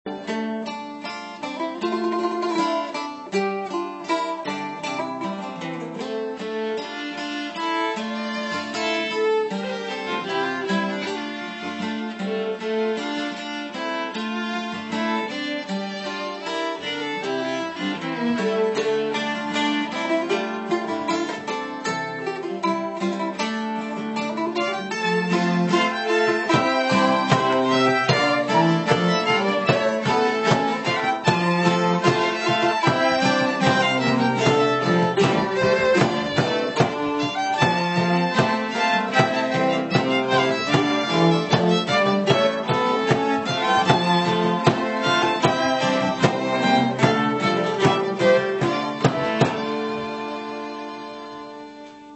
Octave Mandolin
Gypsy Violin
Violin
Viola
Guitar
Violoncello
Bodhran